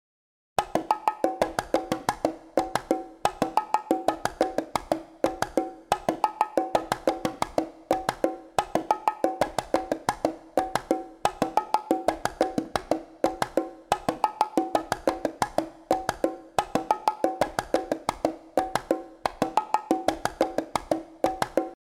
bongos.mp3